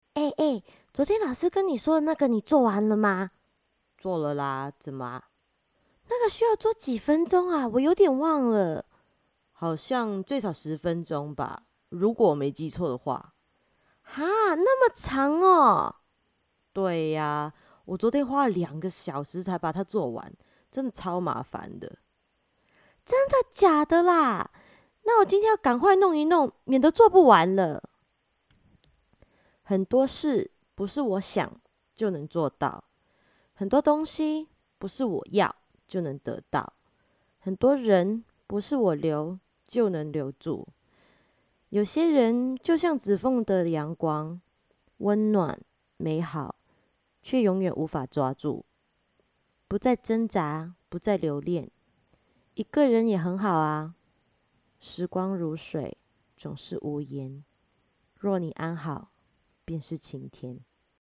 Kommerzielle Demo
AltstimmeNiedrigMezzosopran
Von HerzenFachmannSanftSpielerischKlarNeutral